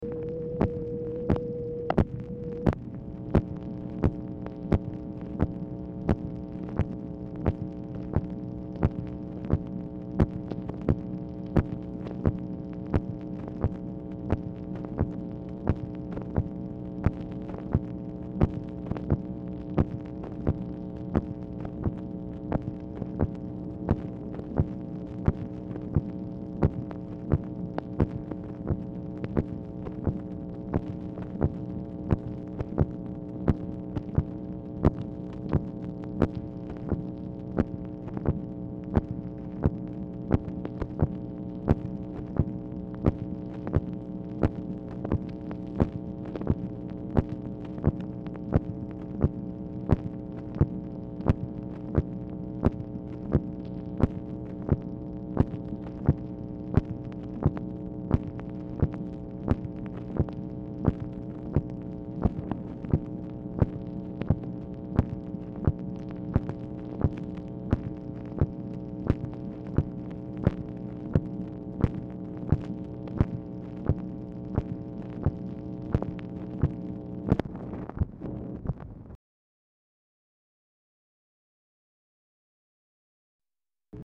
Telephone conversation # 5932, sound recording, MACHINE NOISE, 10/20/1964, time unknown | Discover LBJ
Format Dictation belt